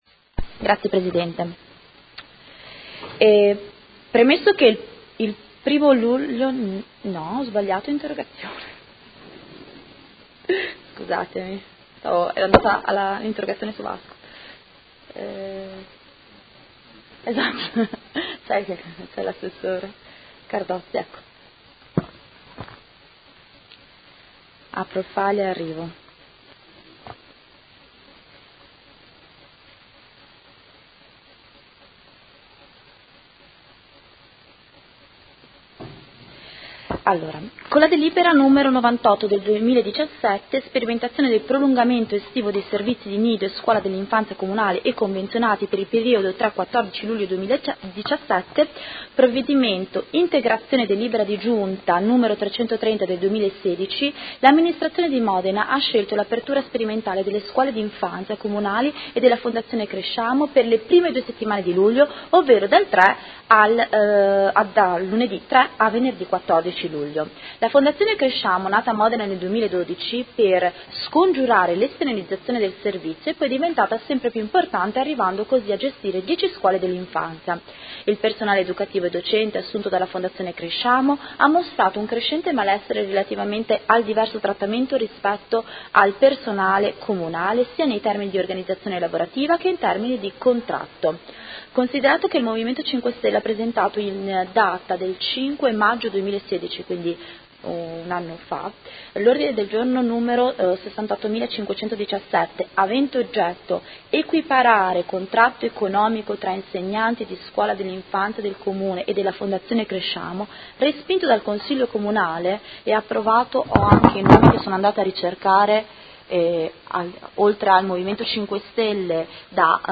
Seduta del 25/05/2017 Interrogazione dei Consiglieri Scardozzi, Bussetti e Rabboni (M5S) avente per oggetto: Servizio estivo nidi e scuola d’infanzia